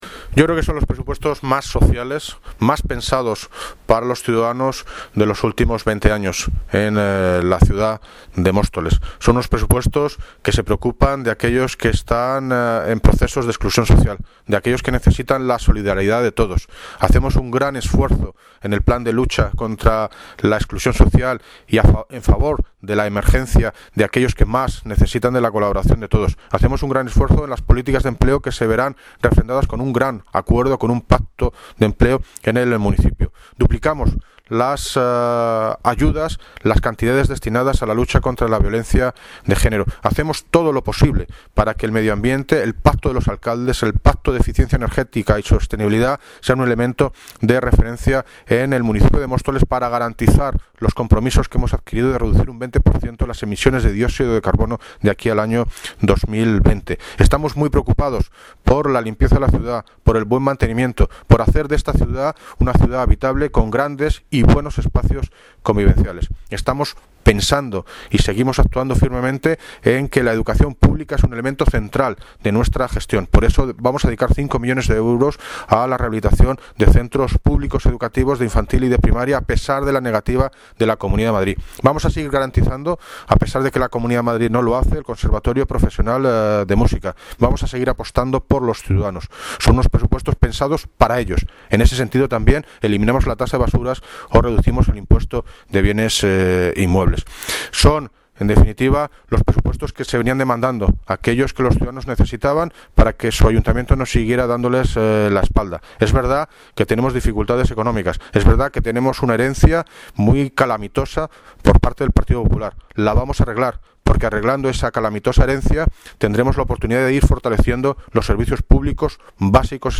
David Lucas (Alcalde de Móstoles) sobre los Presupuestos de 2016